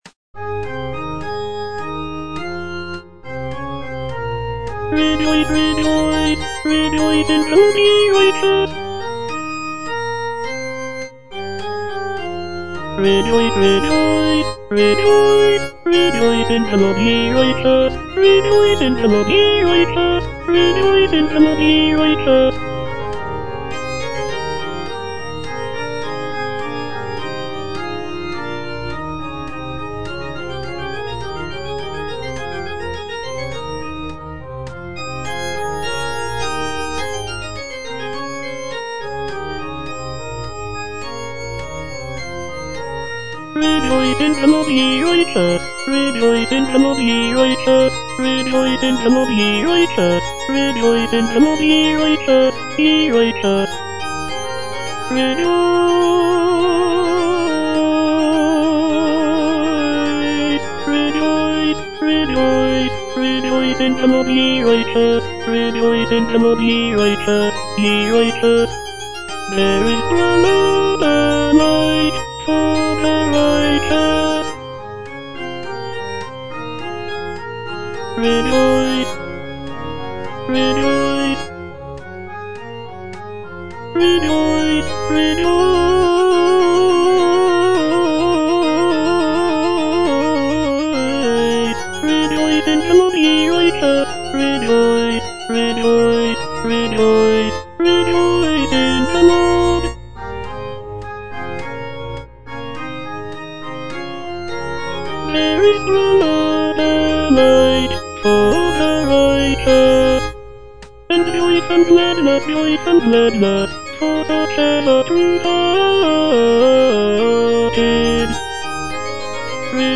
G.F. HÄNDEL - O COME, LET US SING UNTO THE LORD - CHANDOS ANTHEM NO.8 HWV253 (A = 415 Hz) There is sprung up a light - Tenor (Voice with metronome) Ads stop: auto-stop
The use of a lower tuning of A=415 Hz gives the music a warmer and more resonant sound compared to the standard tuning of A=440 Hz.